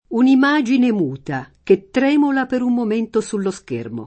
tremolare v.; tremolo [ tr $ molo ] — es. con acc. scr.: un’imagine muta, che trèmola per un momento su lo schermo [ un im #J ine m 2 ta, ke ttr $ mola per um mom % nto S u llo S k % rmo ] (Pirandello)